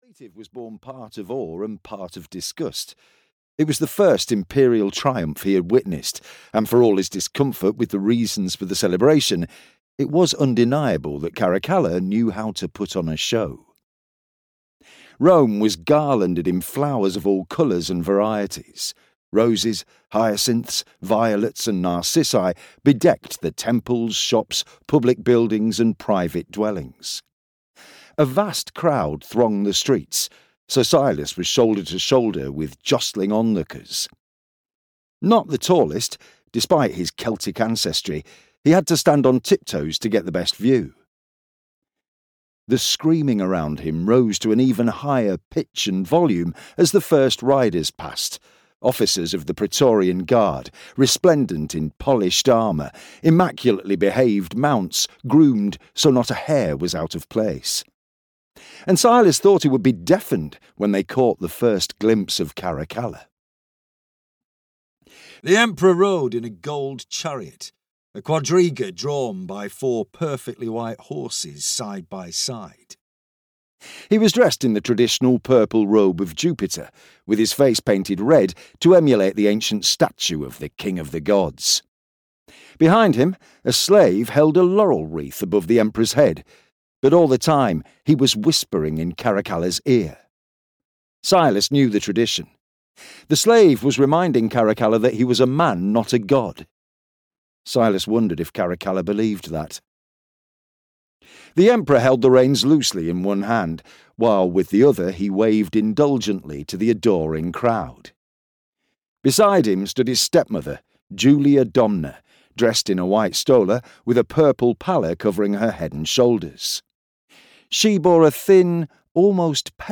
Emperor's Lion (EN) audiokniha
Ukázka z knihy